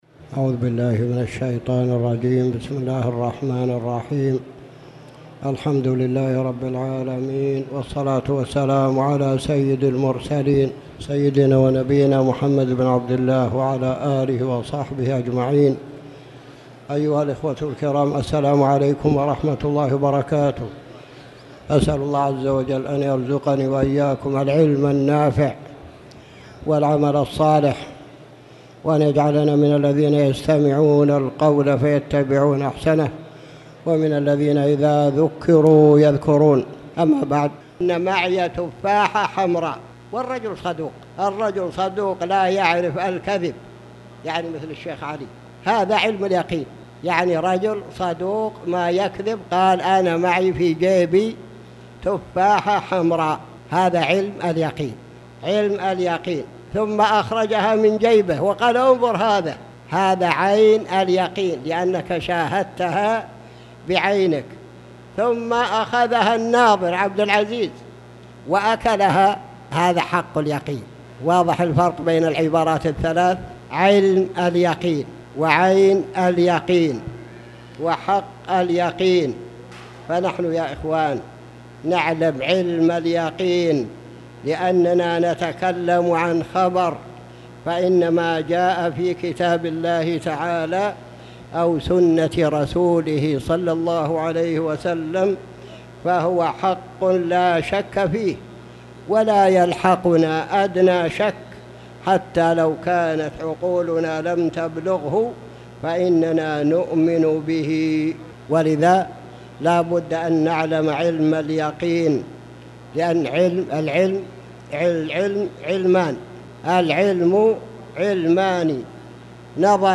تاريخ النشر ١٨ صفر ١٤٣٩ هـ المكان: المسجد الحرام الشيخ